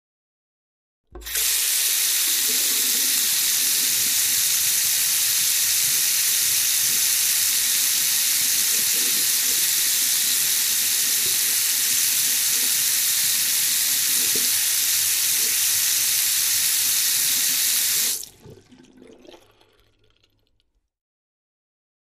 Wash Basin Glass, Heavy Stream